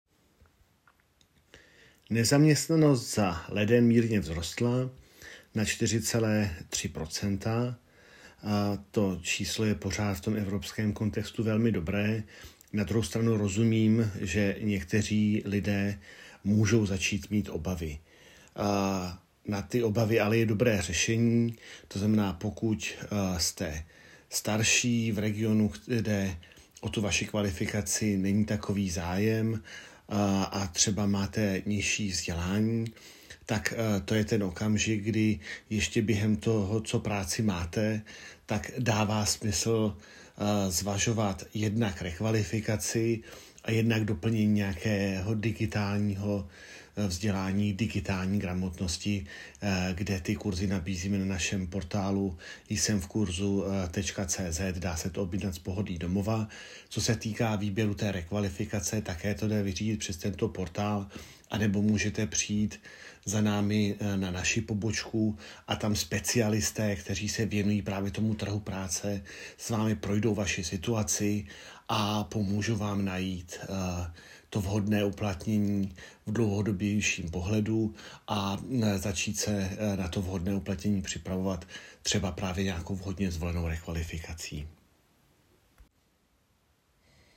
NEZAM_leden 25_zvuk koment_GŘ ÚP Daniel Krištof.m4a